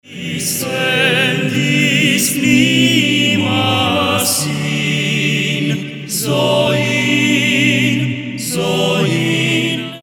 four male voices
Byzantine Orthodox Songs